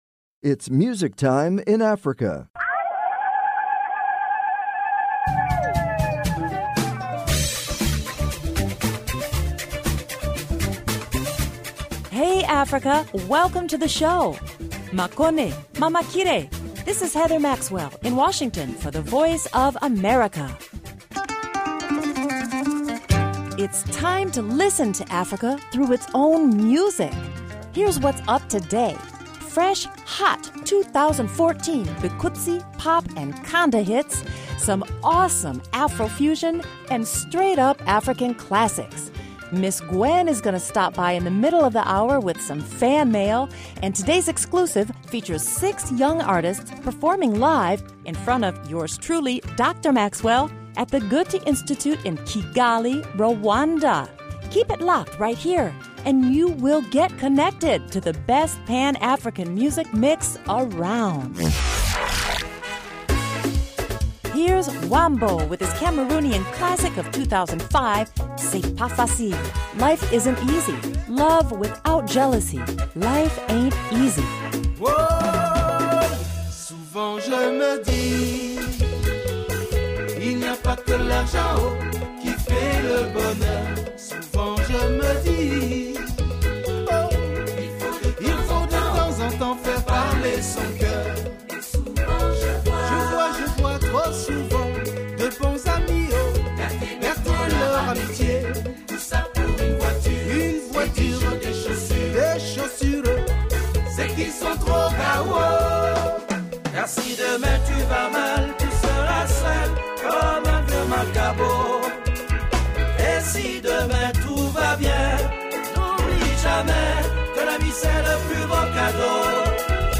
Music Time in Africa is VOA’s longest running English language program. Since 1965, this award-winning program has featured pan African music that spans all genres and generations.